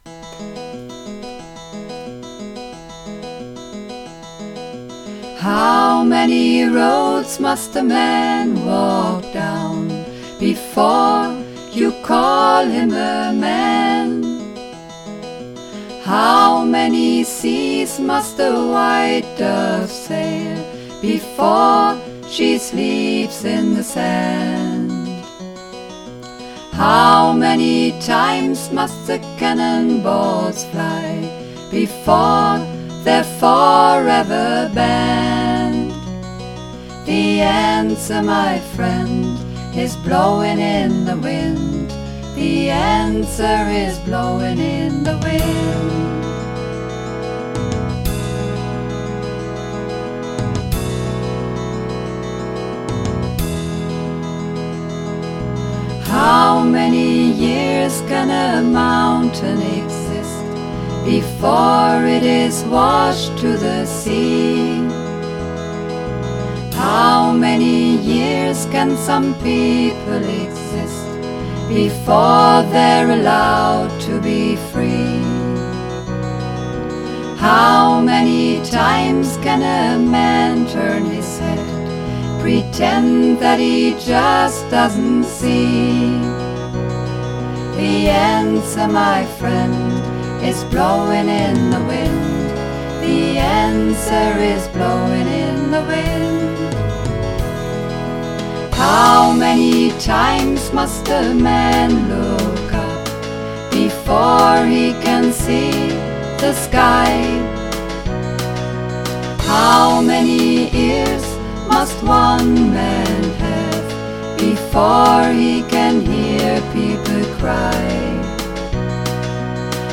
Übungsaufnahmen
Runterladen (Mit rechter Maustaste anklicken, Menübefehl auswählen)   Blowin' In The Wind (Mehrstimmig)
Blowin_In_The_Wind__4_Mehrstimmig.mp3